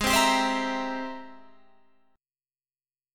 Ab+M9 chord